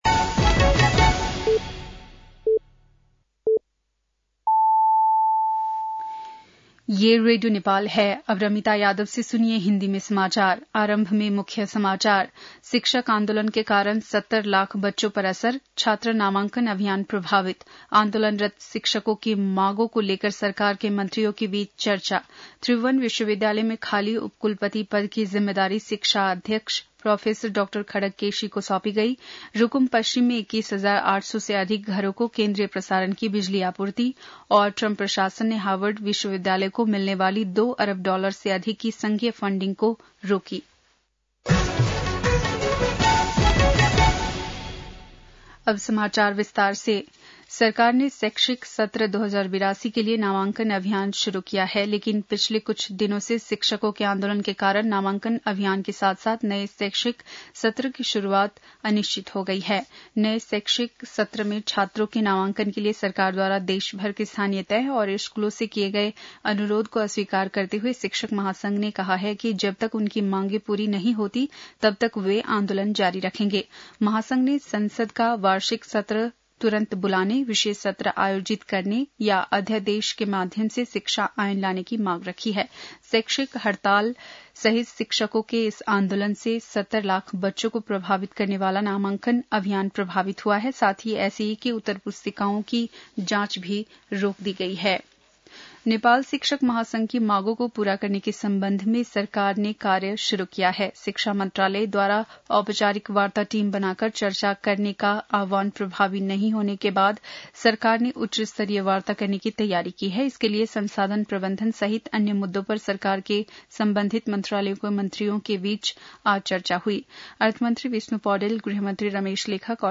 बेलुकी १० बजेको हिन्दी समाचार : २ वैशाख , २०८२
10-pm-hindi-news-1-02.mp3